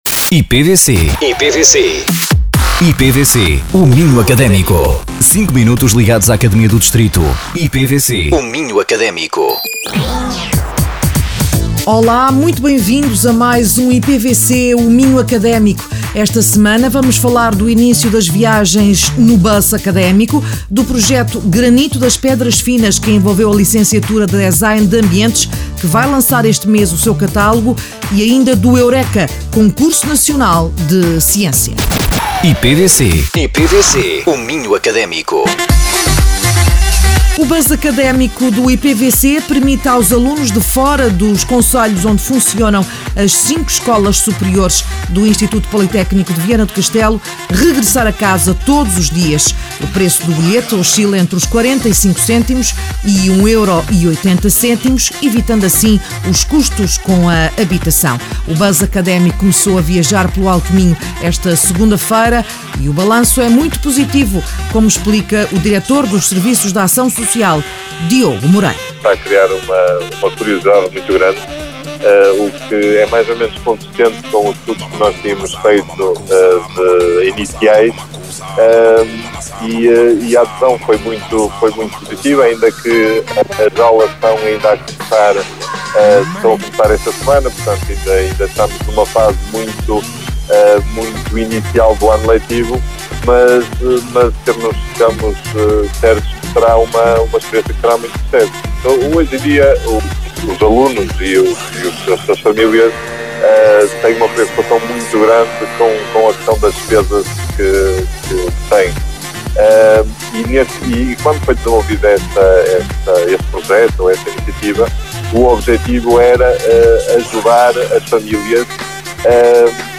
O programa é transmitido todas as quartas-feiras às 11h00, 13h00 e 17h00 e aos domingos às 14h00 e às 20h00.
Entrevistados: